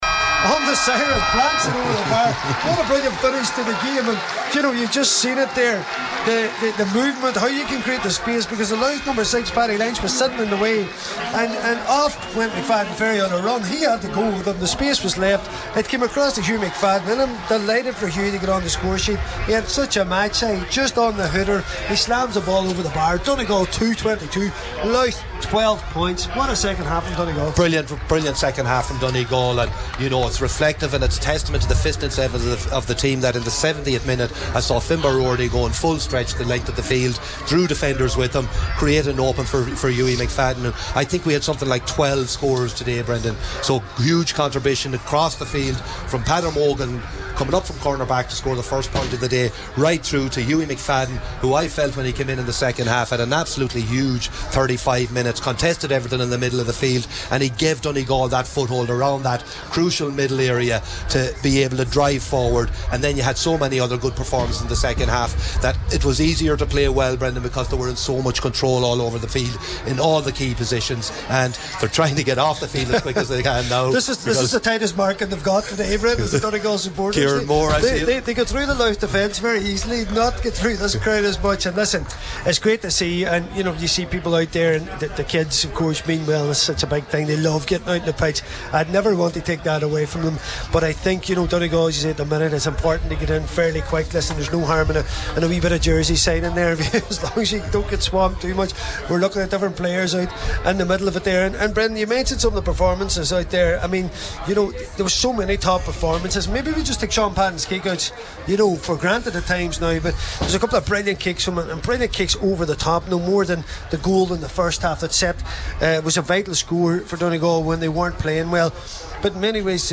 Donegal run out comfortable winners over Louth – Post-Match Reaction
were live at full time for Highland Radio Sport…